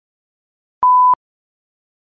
دانلود صدای سانسور 2 از ساعد نیوز با لینک مستقیم و کیفیت بالا
جلوه های صوتی